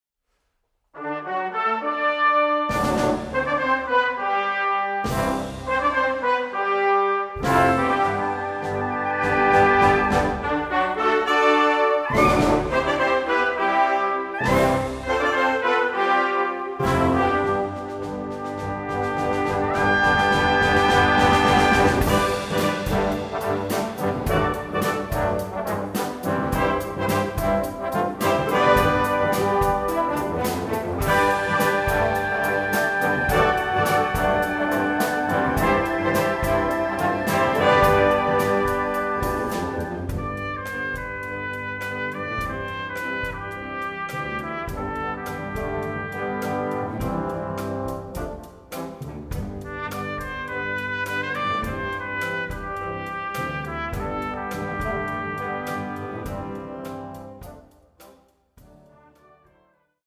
Musik für sinfonisches Blasorchester
Sinfonisches Blasorchester